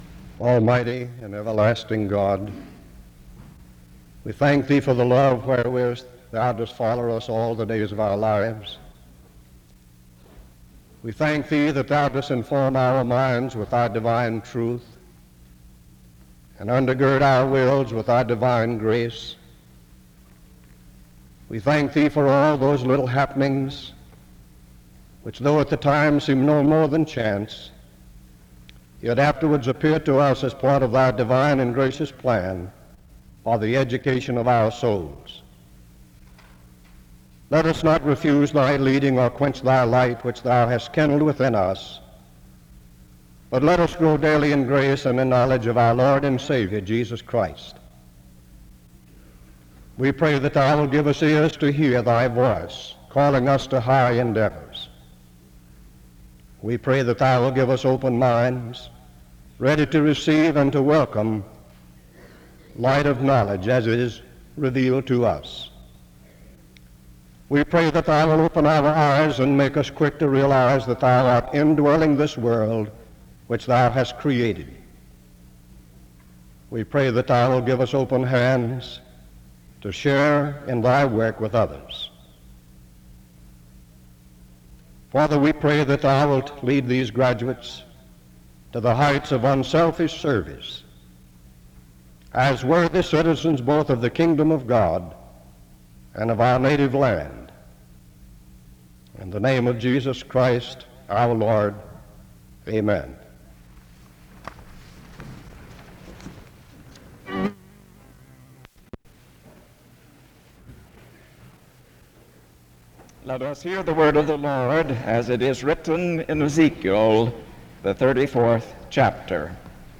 The service begins with a prayer from 0:00-1:43. Ezekiel 34:2-15 is read from 1:53-4:25. A prayer is offered from 4:26-7:06. The graduating class presents their class gift from 7:16-8:47.
A closing prayer is offered from 41:48-42:15.